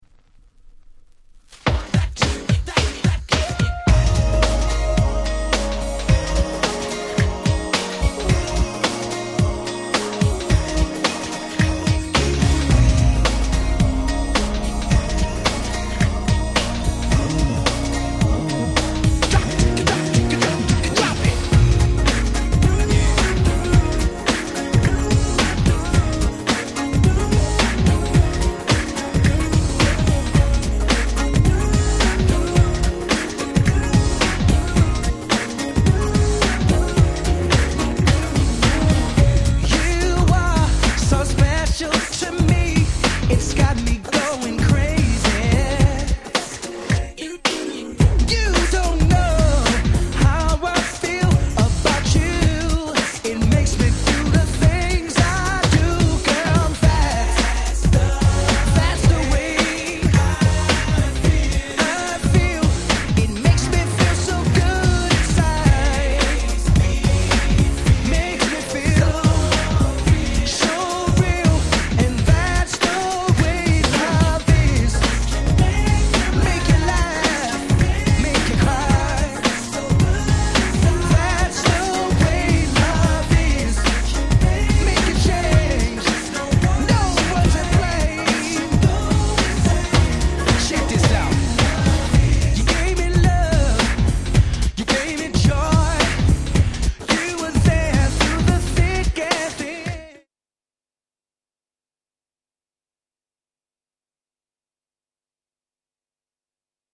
New Jack Swing !!